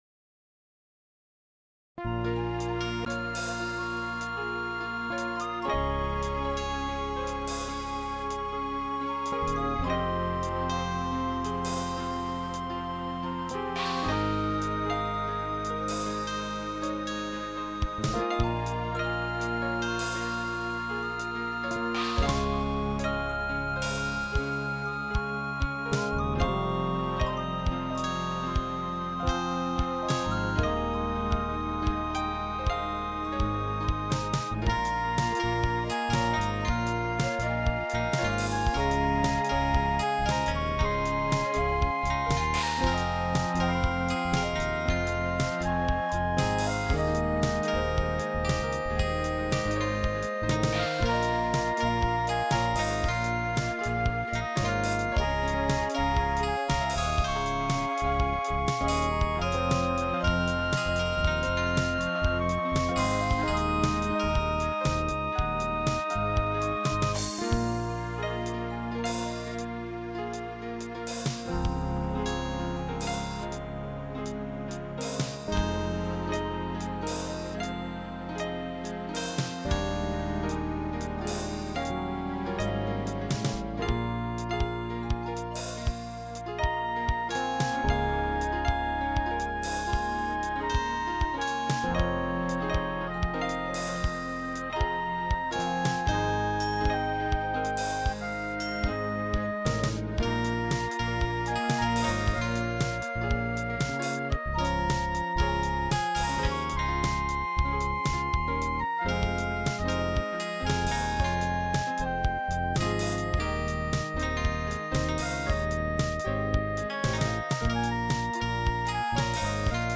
Merry tune with Piccolo, Flute, Koto, Pan Flute, String Esemble and Acoustic Grand,Fretless Bass If you like it feel free to comment belowI'm not solid on drums yet still need more practice with that.